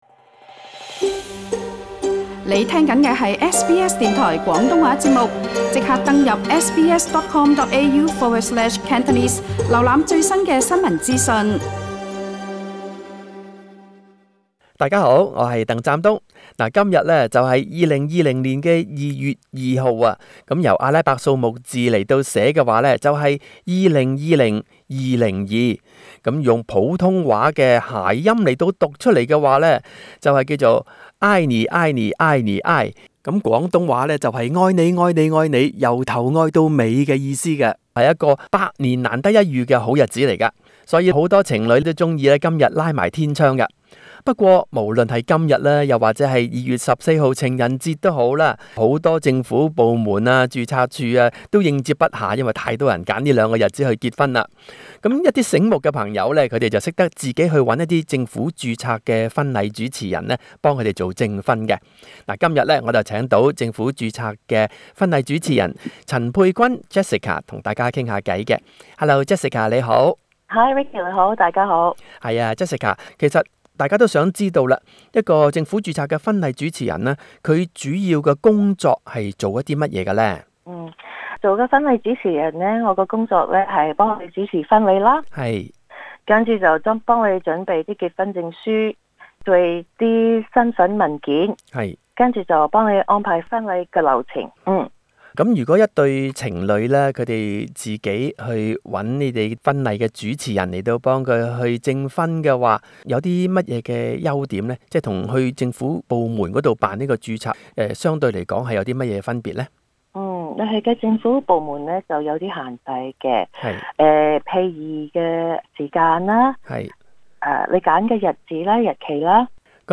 的訪問